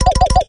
sgg_out_of_ammo_02.ogg